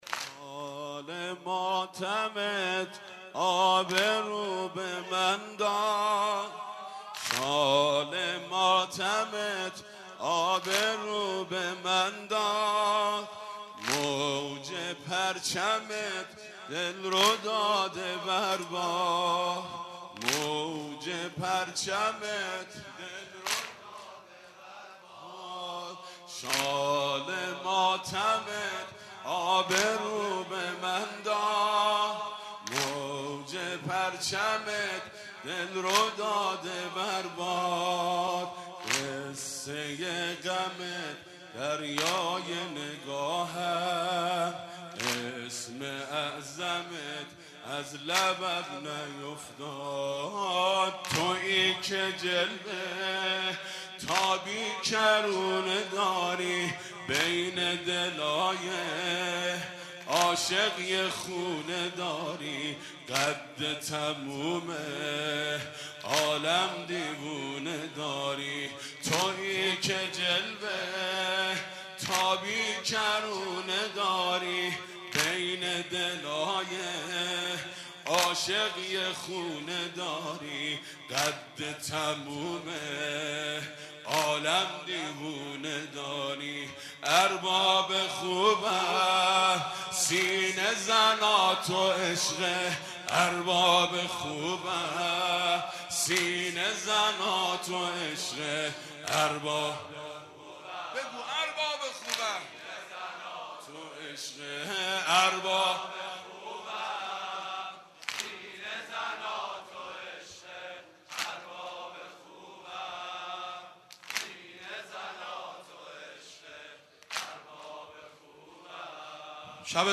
هیئت حیدریه خوانسار
مداحی